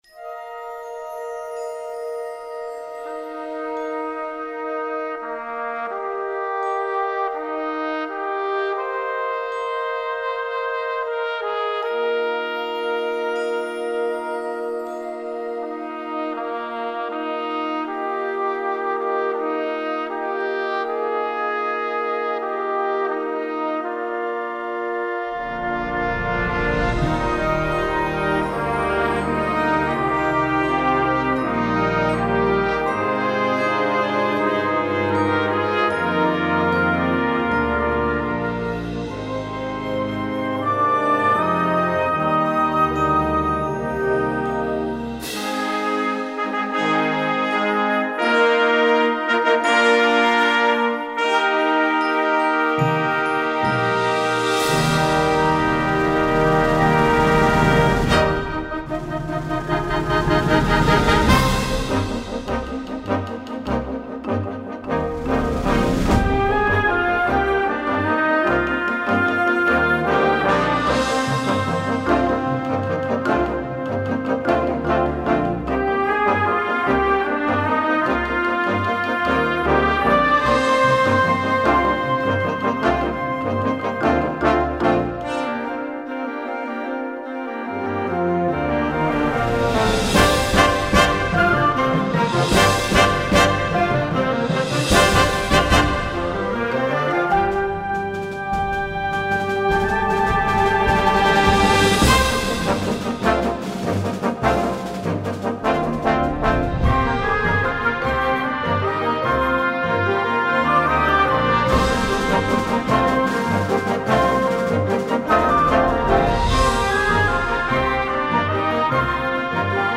Gattung: Konzertwerk
Besetzung: Blasorchester
Aufregend, intensiv und pädagogisch wertvoll